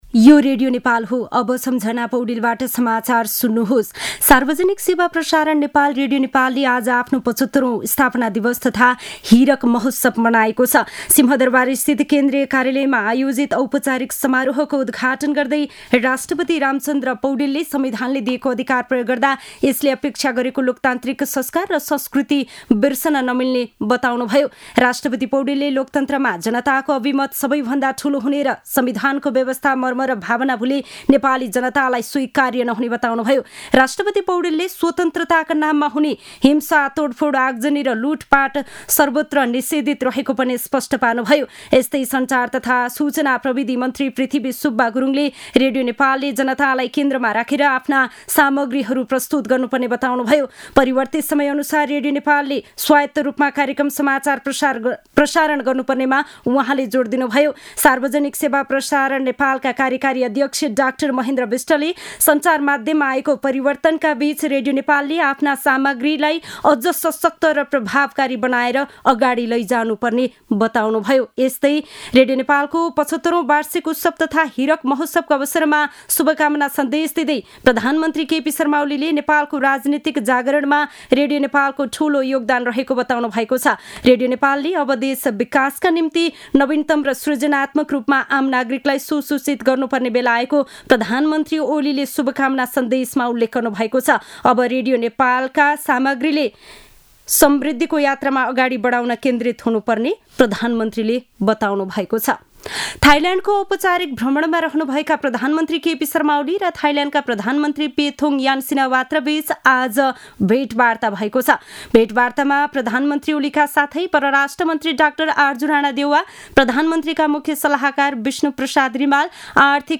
दिउँसो १ बजेको नेपाली समाचार : २० चैत , २०८१